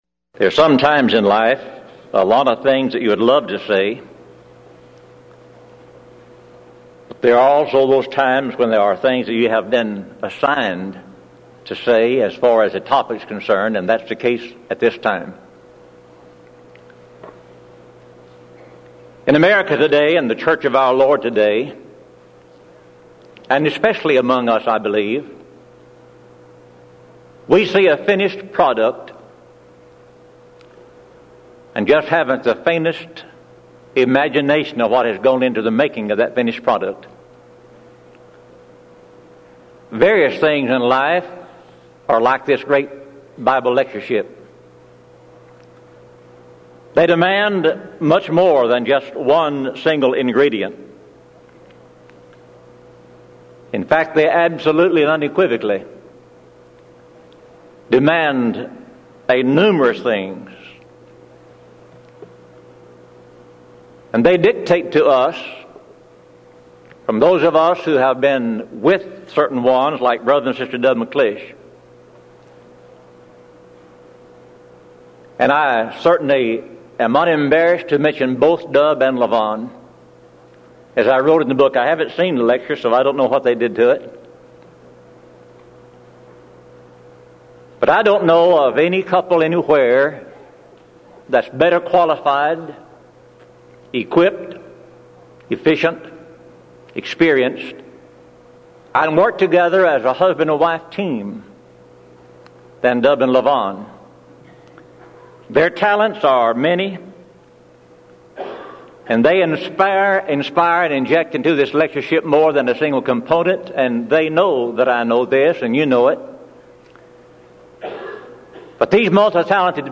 Event: 1995 Denton Lectures Theme/Title: Studies In Matthew